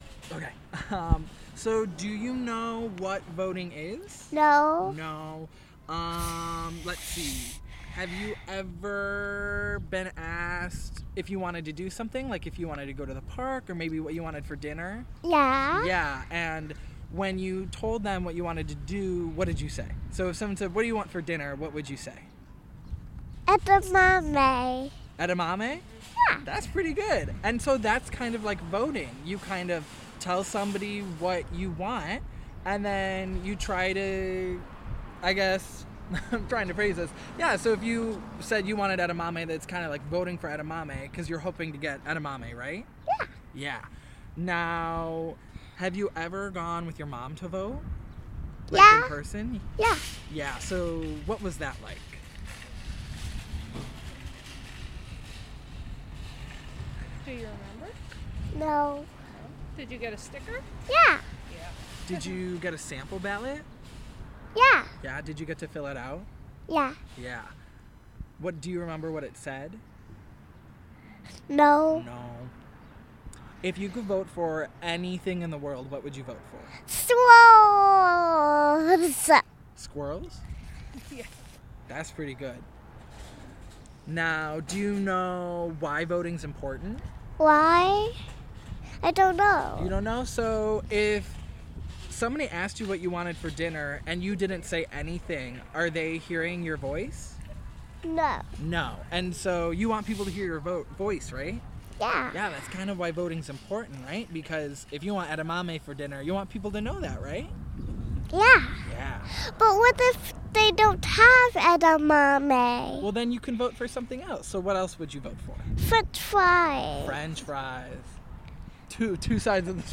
Location Outpost Natural Foods